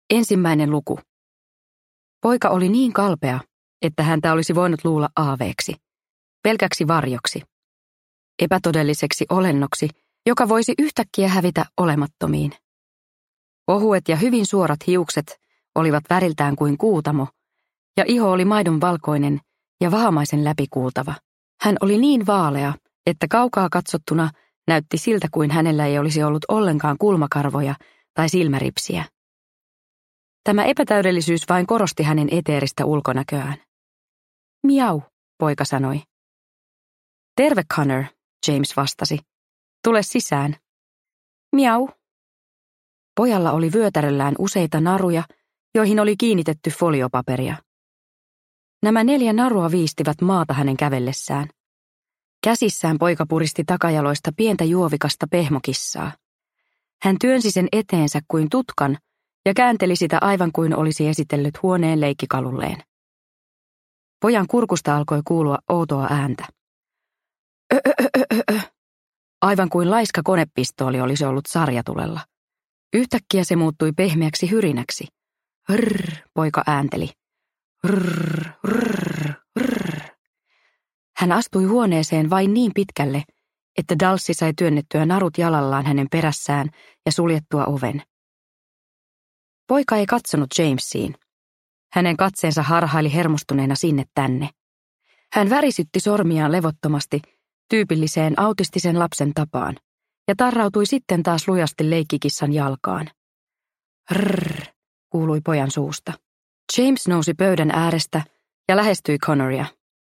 Sähkökissa – Ljudbok – Laddas ner